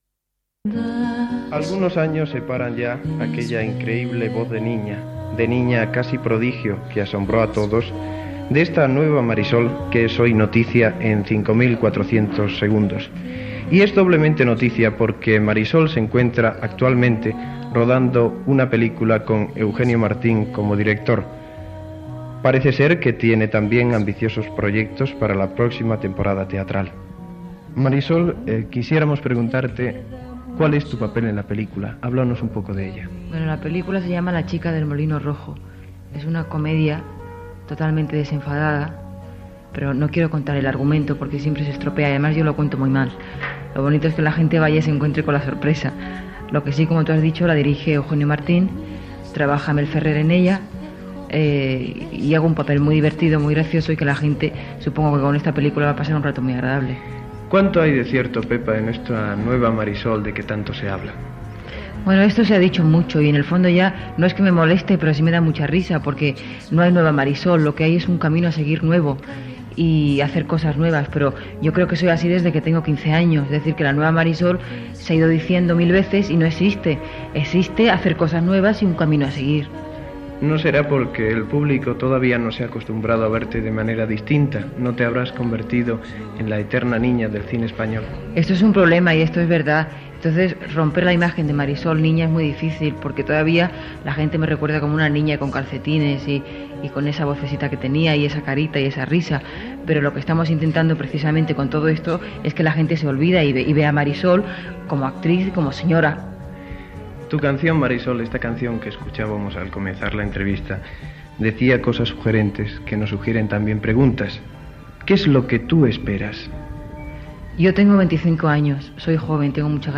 Entrevista a Pepa Flores "Marisol" en el rodatge de la pel·lícula "La chica en el molino rojo".
Informatiu
Els programa estava dirigit i presentat per Tico Medina.